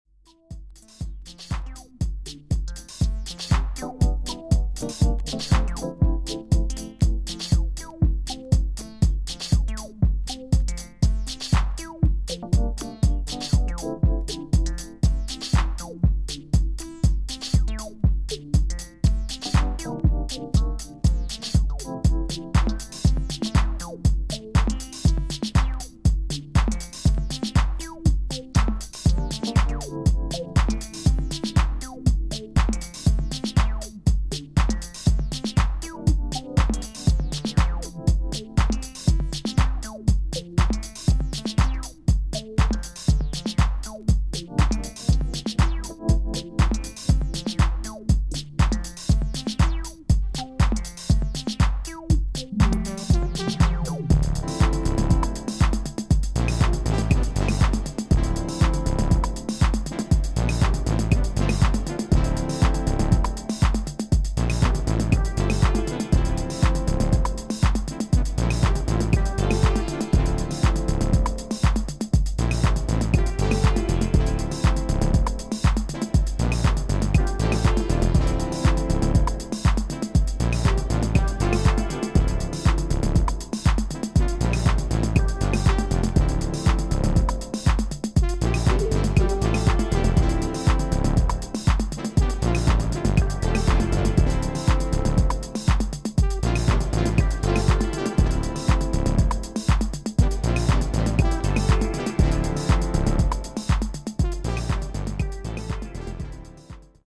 HOUSE/BROKEN BEAT